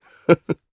Riheet-Vox_Happy1_jp.wav